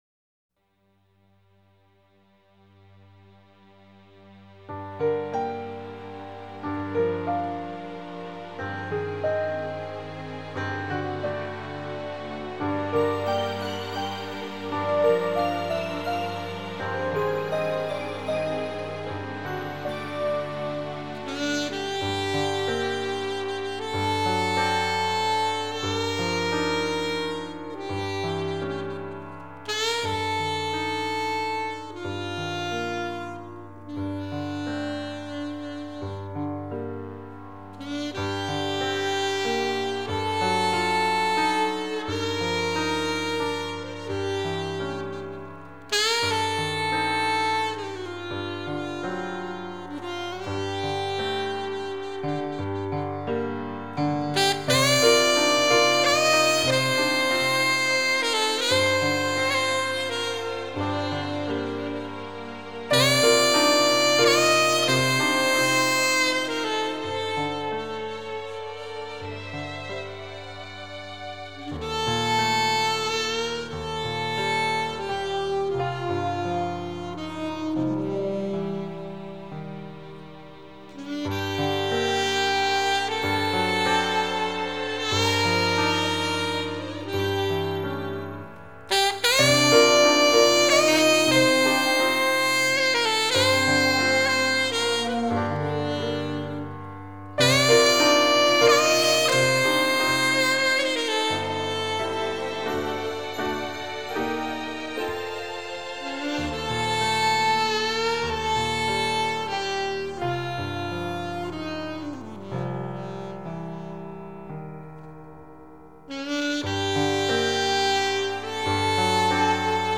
Genre: Instrumental Pop.